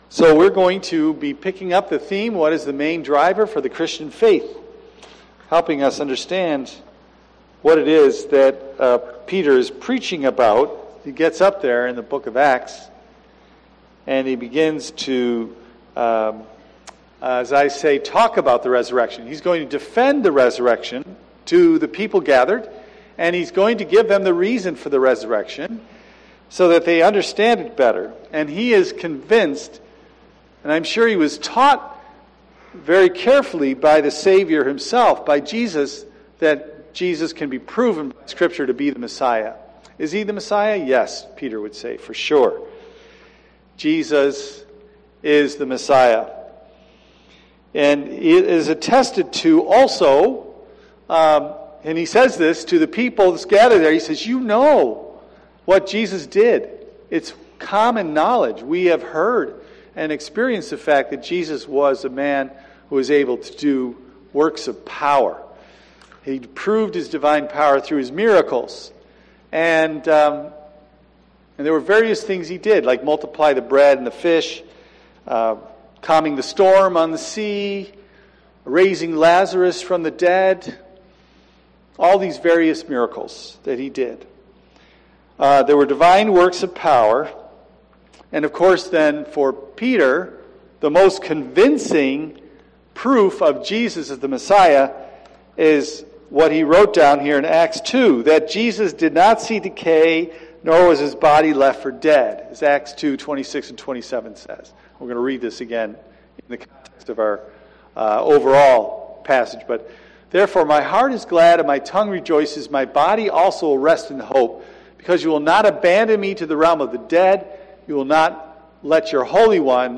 Now, I am going to preach a Reformation sermon for you today. there is a unity and a disunity with Roman Catholic doctrine.